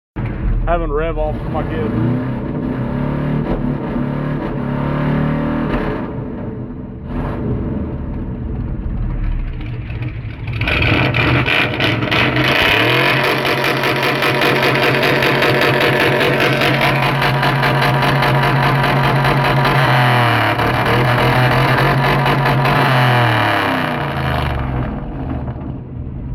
tired big block vs 6.0 sound effects free download